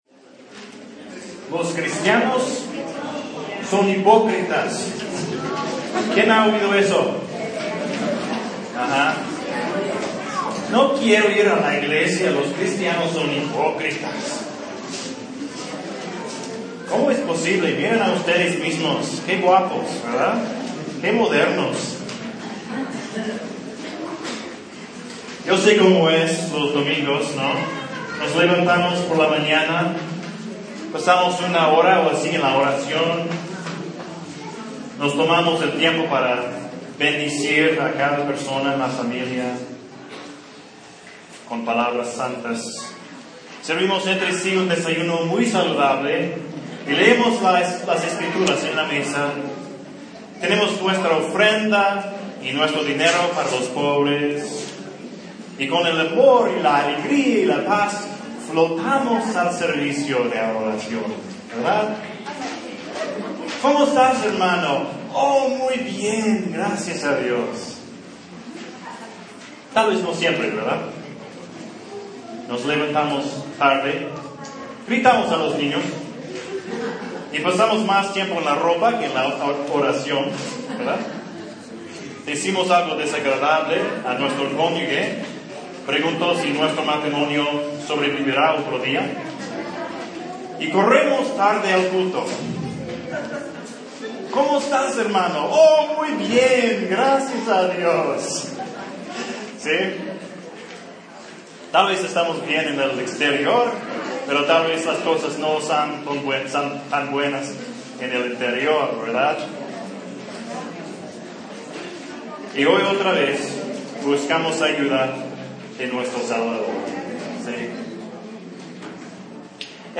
La Santificación (sermón)
Un sermón sobre la santificación en la Biblia. ¿Qué es, y por qué es importante?